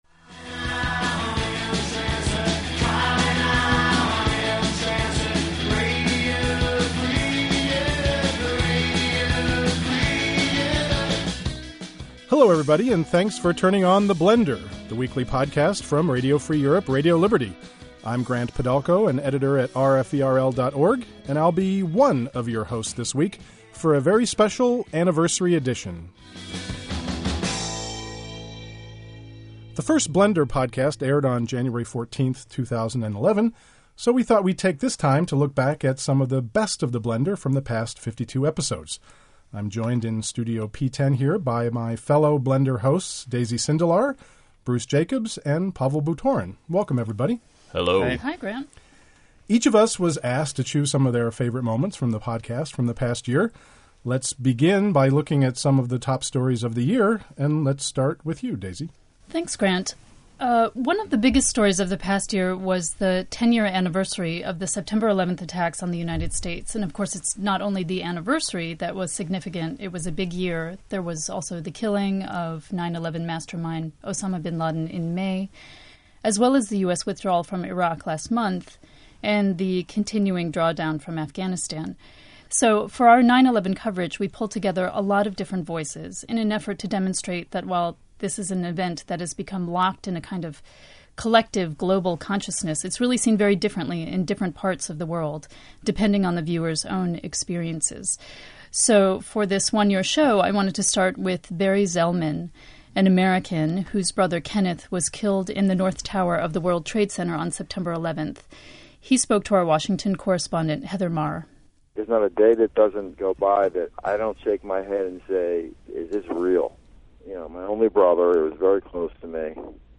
"The Blender" podcast presents the best of RFE/RL -- features from our far-flung bureaus, interviews with newsmakers and correspondents, reports on language, music, and culture, even a little humor now and again.